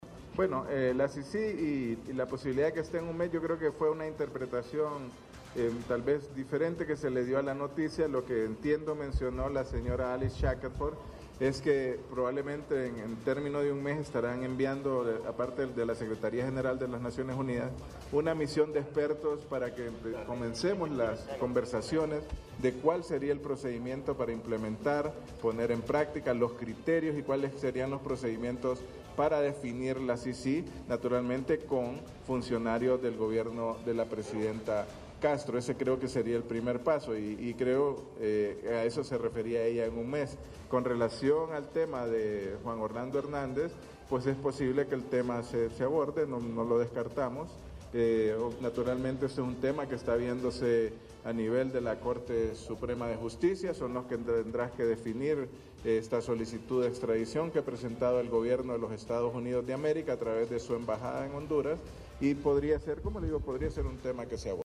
Canciller Eduardo Reina, durante la conferencia de prensa. Foto / HRN.